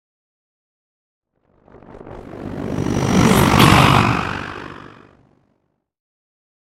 Scifi whoosh pass by chopper
Sound Effects
futuristic
high tech
pass by